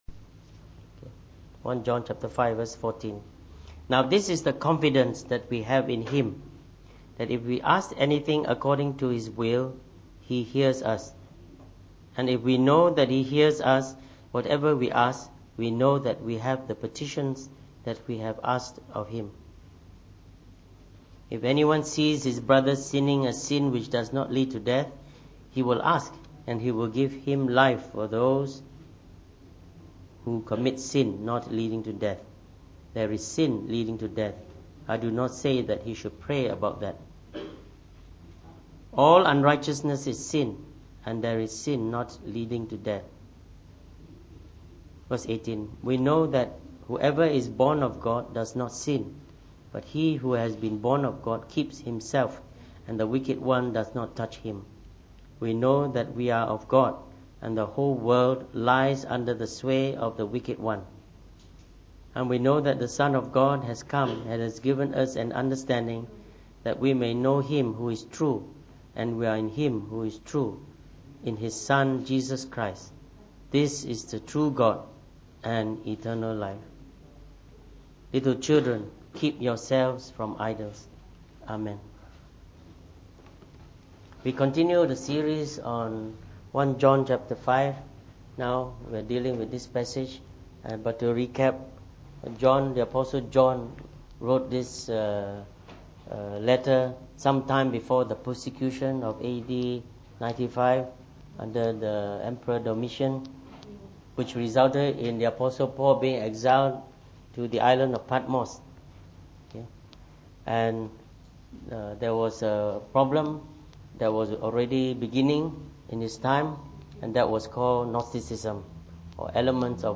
From our series on the Book of 1 John delivered in the Morning Service.